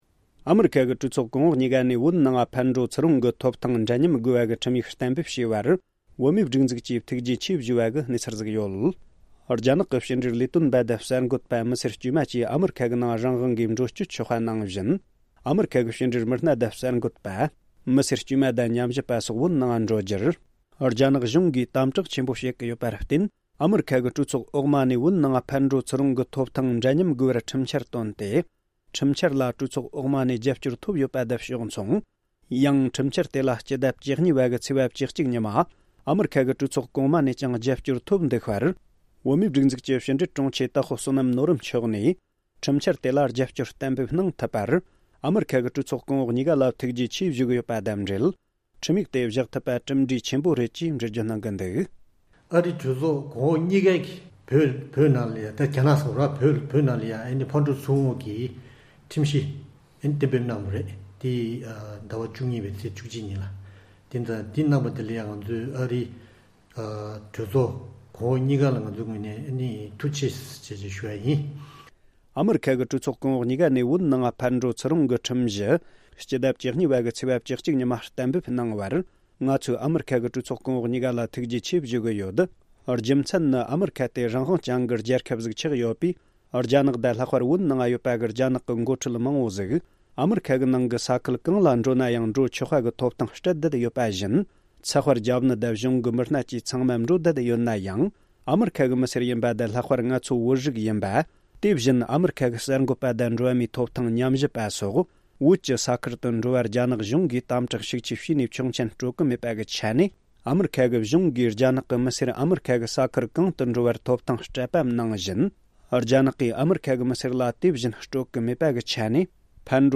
སྲིད་སྐྱོང་མཆོག་གིས་ཐུགས་རྗེ་ཞུས་པའི་གནས་ཚུལ། སྒྲ་ལྡན་གསར་འགྱུར།